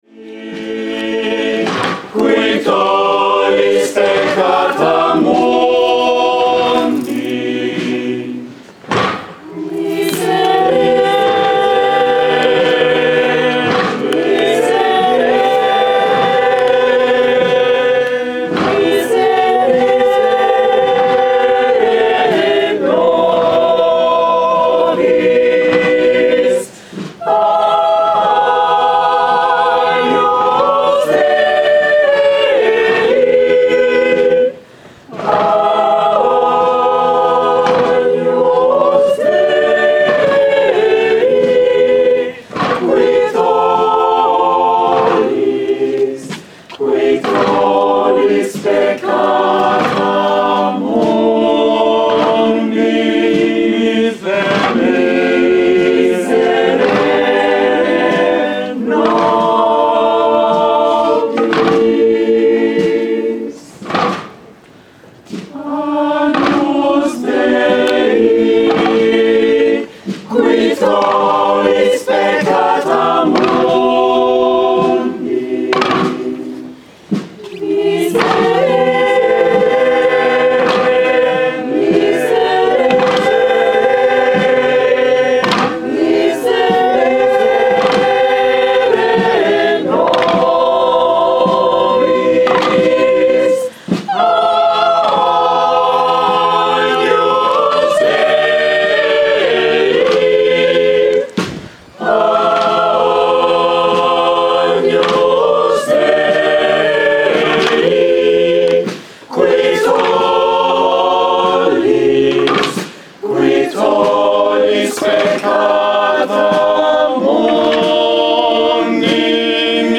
Procesión del Silencio - Semana Santa 2014